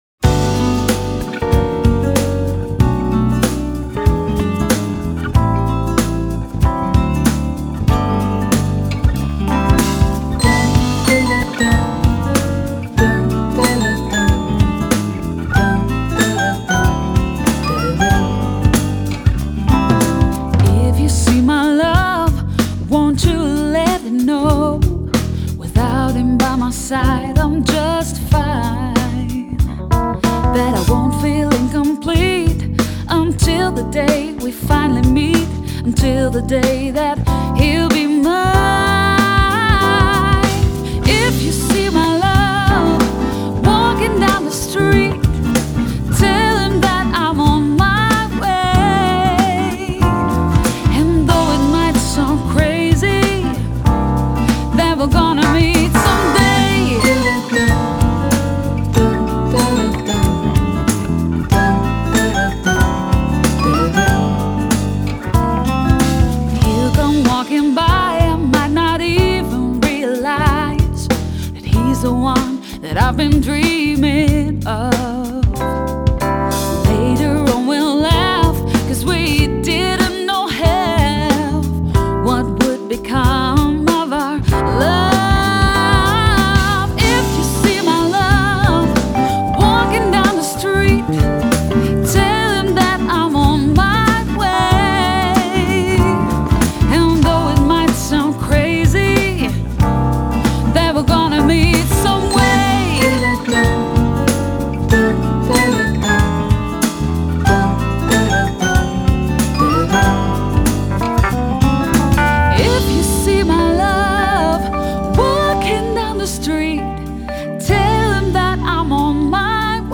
Genre: Pop, Folk Pop, Jazzy Pop